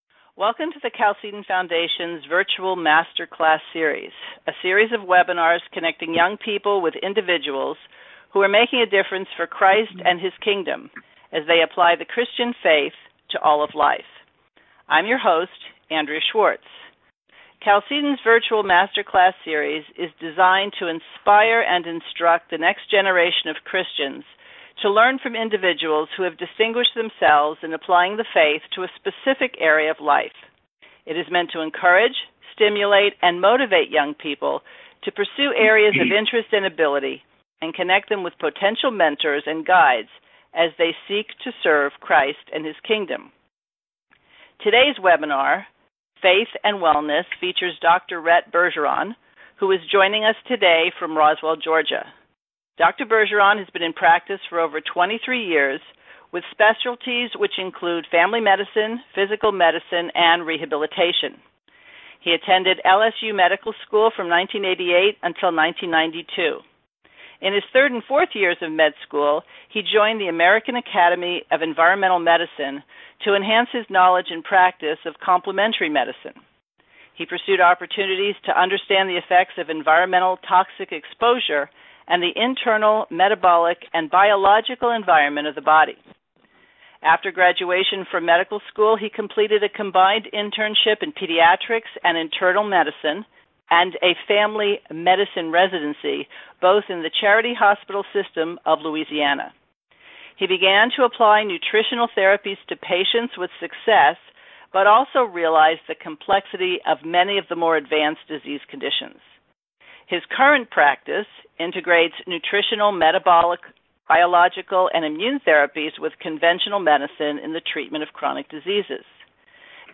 spent an hour with some young people and their parents during an online webinar